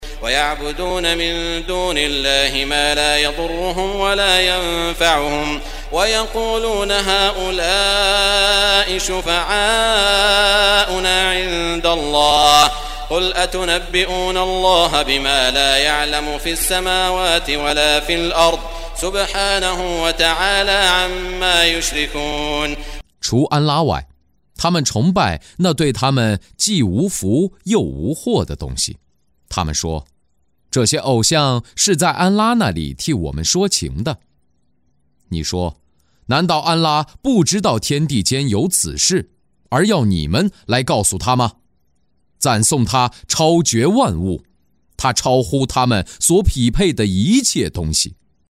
中文语音诵读的《古兰经》第（优努斯）章经文译解（按节分段），并附有诵经家沙特·舒拉伊姆的诵读